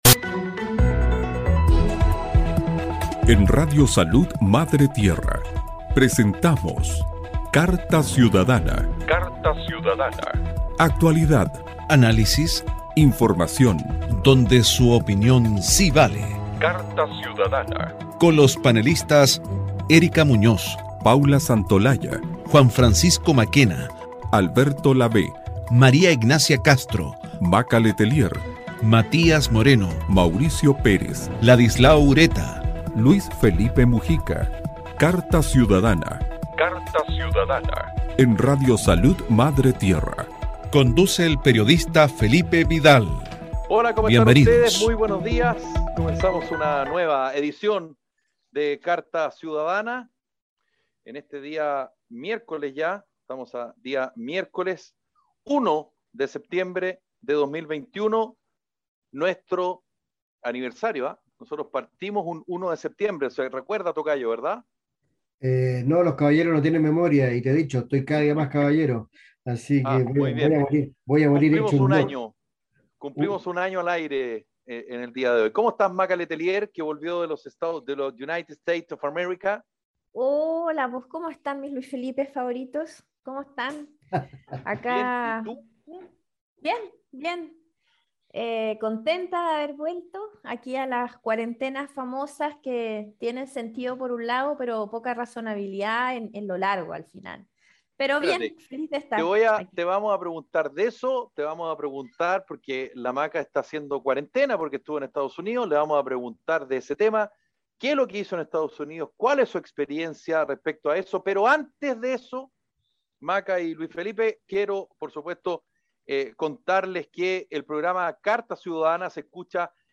programa de conversación y análisis de la contingencia en Chile.